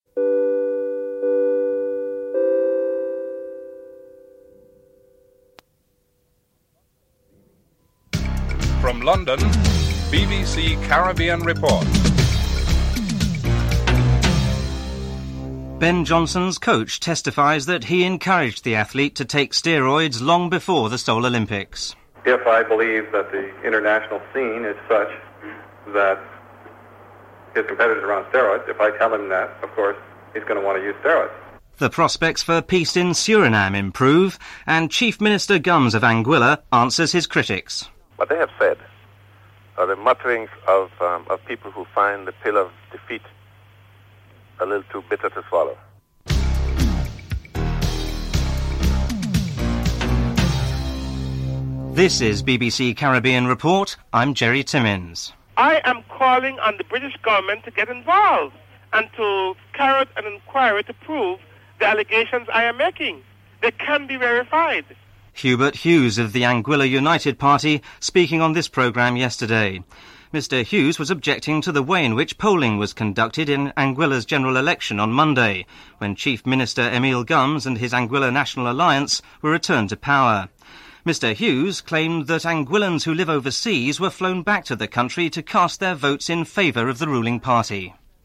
2. Recording of Hubert Hughes on the previous day's BBC Caribbean Report on irregularities in Anguilla's recent elections (00:53-01:50)